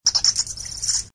Archivo:Grito de Noibat.ogg